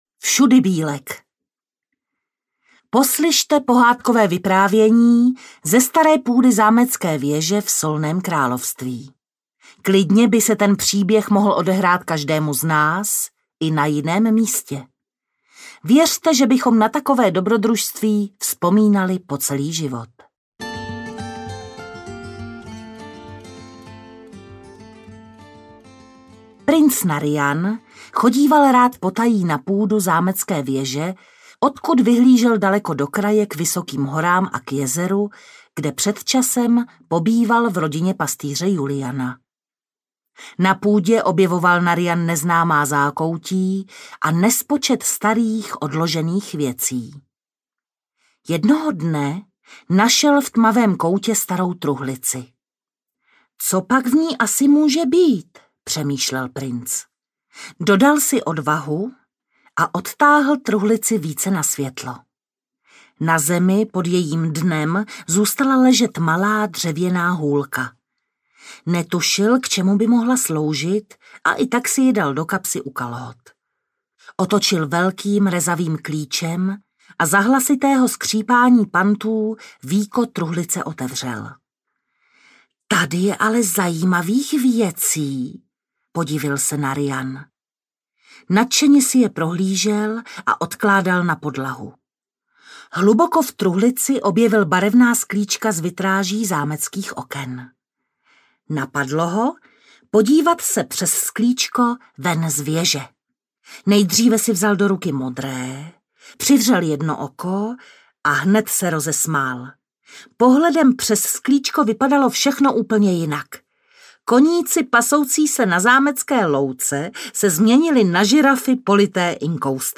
Bylinkové pohádky audiokniha
Ukázka z knihy